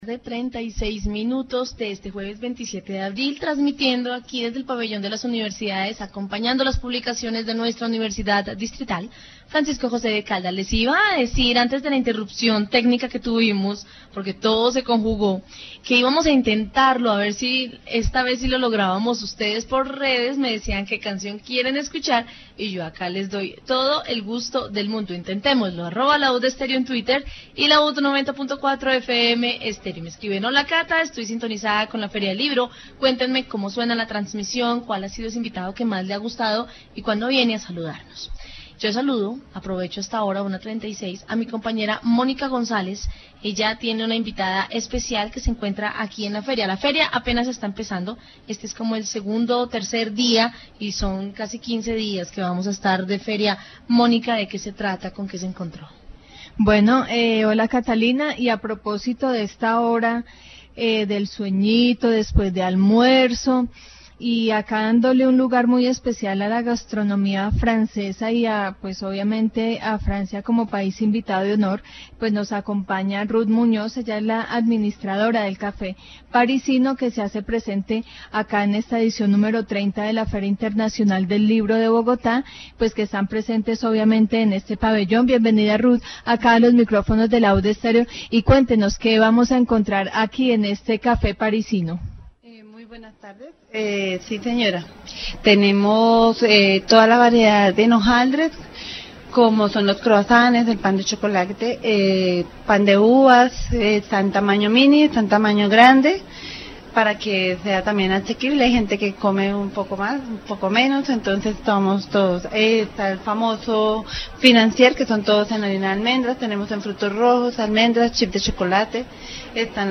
Feria del Libro 2017. Informe radial
Programas de radio